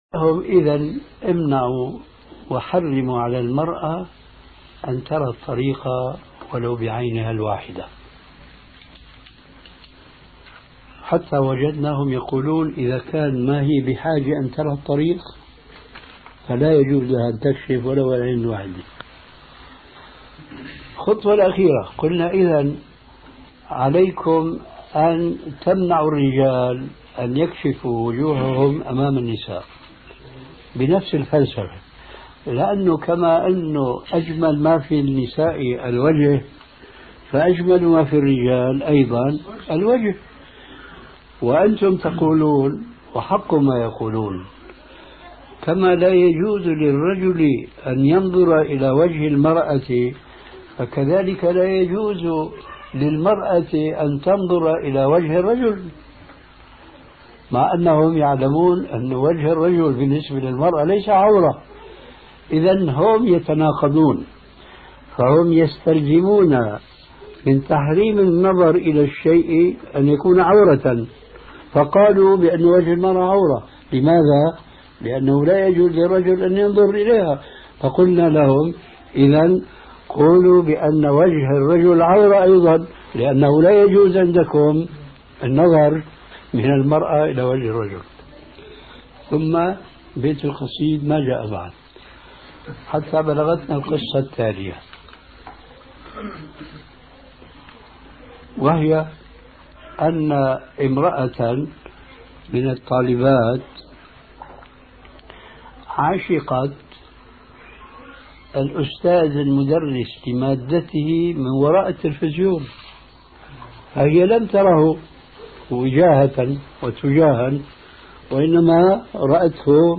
أرشيف الإسلام - ~ أرشيف صوتي لدروس وخطب ومحاضرات الشيخ محمد ناصر الدين الألباني